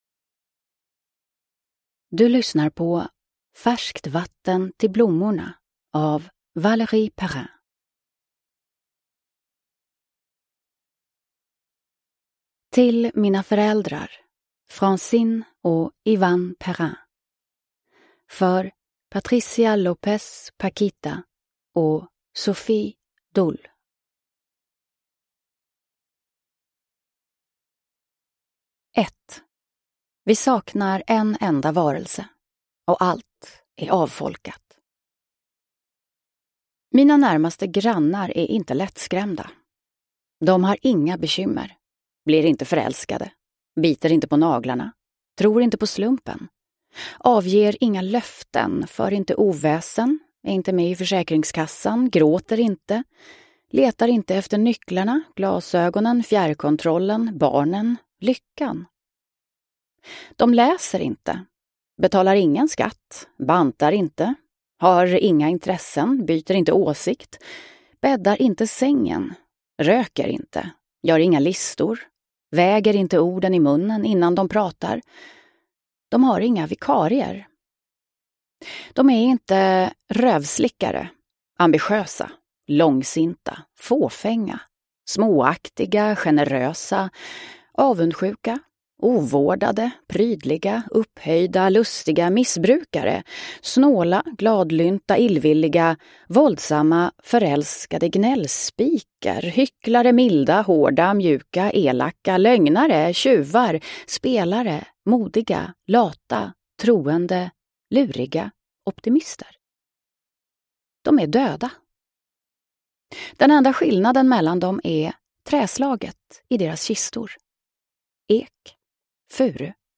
Färskt vatten till blommorna – Ljudbok – Laddas ner